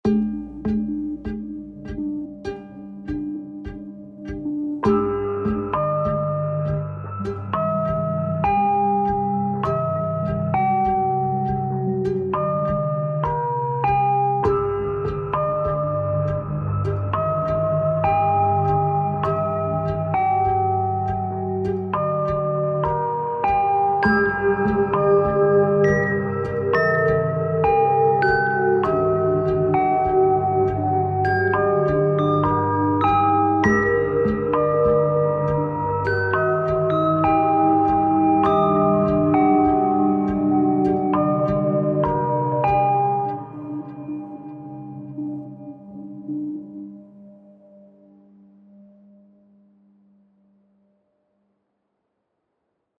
Horror. Nightmares and nerve-wracking tension